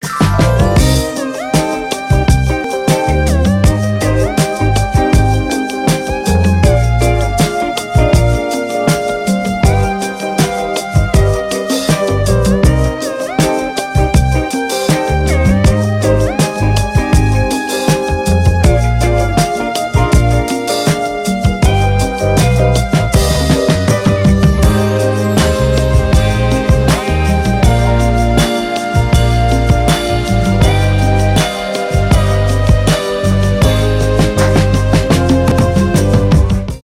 без слов , блюз